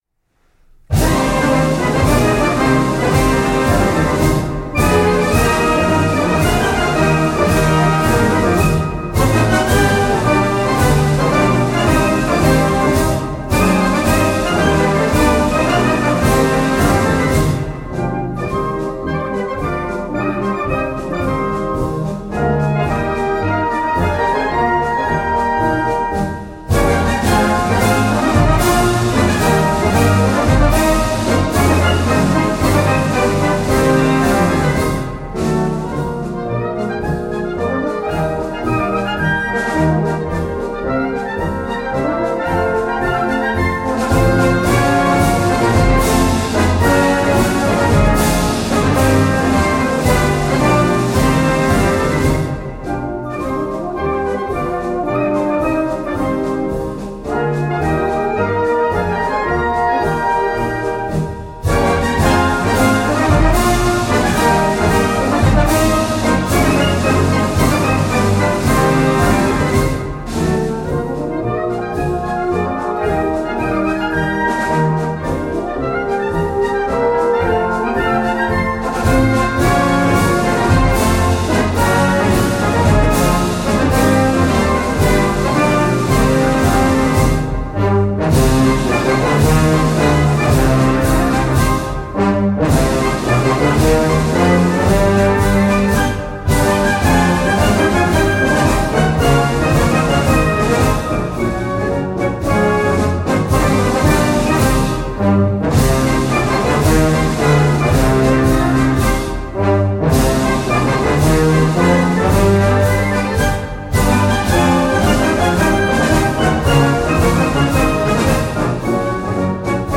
harmonieorkest
Marsmuziek